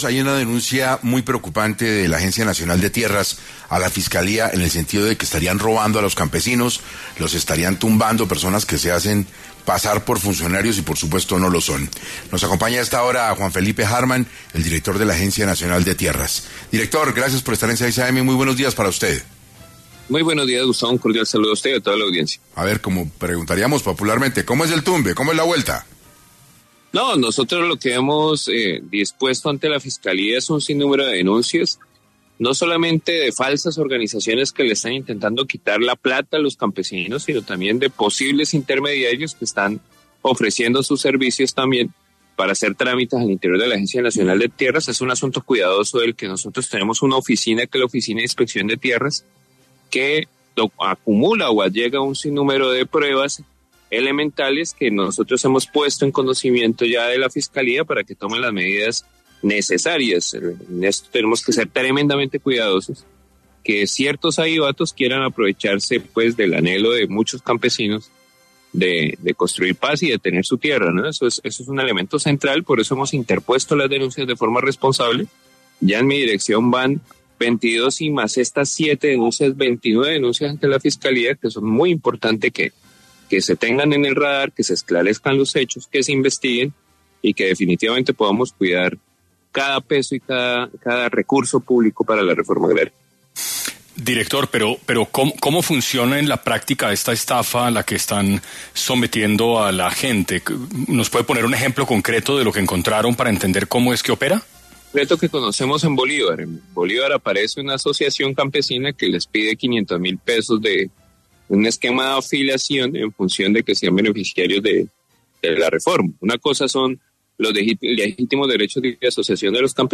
En Caracol Radio estuvo Juan Felipe Harman Ortiz, director de la Agencia Nacional de Tierras (ANT).